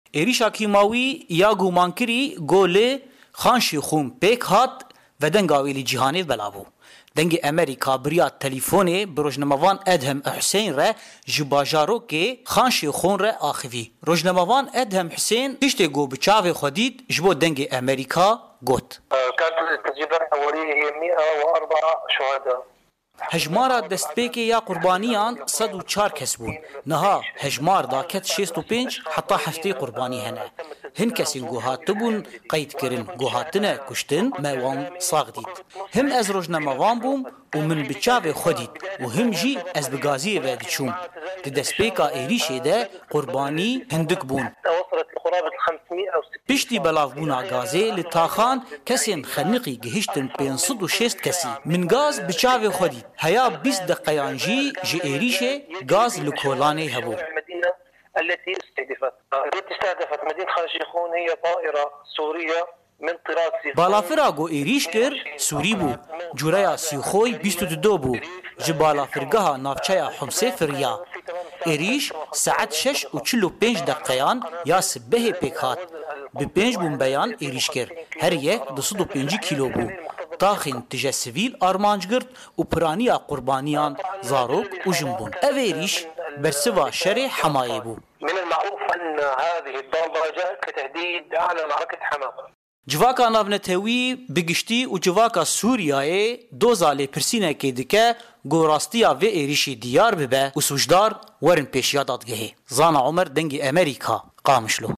ڕۆژنامەوانێـک باس لە وردەکاری ئەو هێرشە ئاسمانیـیە دەکات کە ڕۆژی سێشەممە کرایە سەر شاری خان شەیخونی پارێزگای ئیدلیب لە باکوری ڕۆژئاوای سوریا و بەهۆیەوە دەیان کەس کوژران و سەدانی دیکە برینداربوون